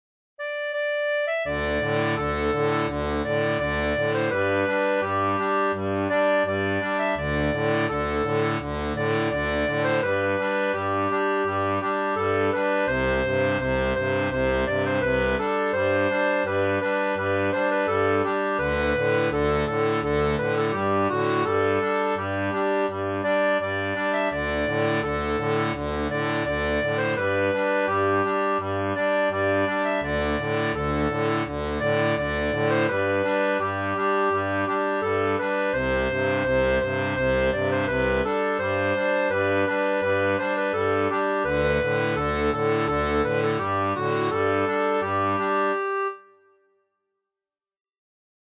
Air traditionnel portugais
Folk et Traditionnel